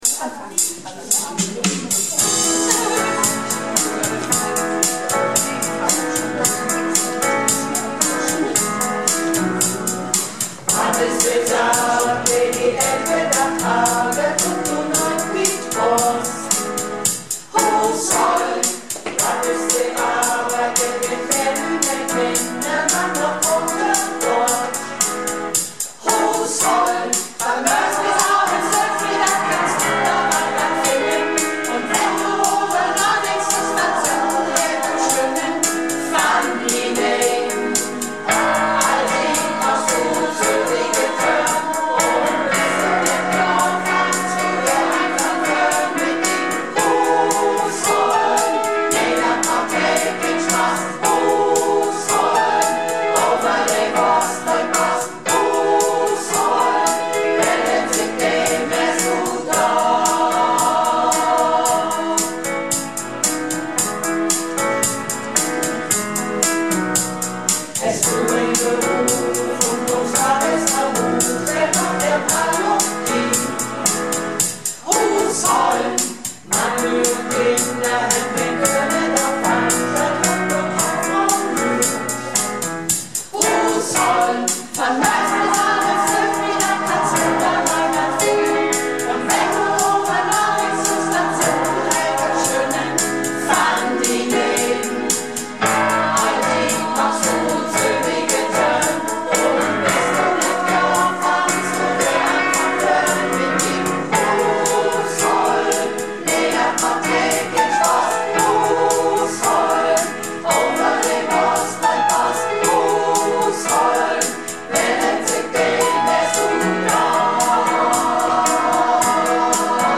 Malle Diven - Probe am 18.01.20